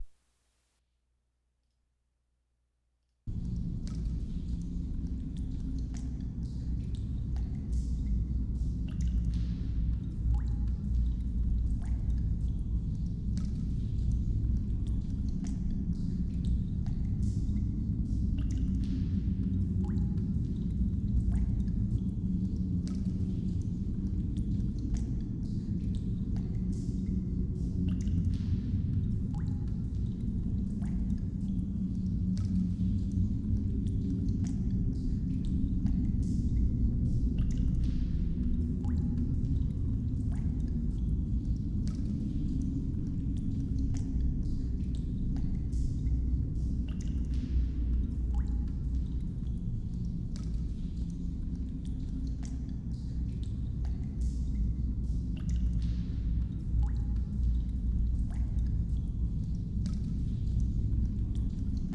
Imagine this as a colossal, sinuous wind instrument or voice.
Click anywhere on the image, above, to hear the voice of a cave.